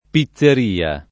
pizzeria [ pi ZZ er & a ] s. f.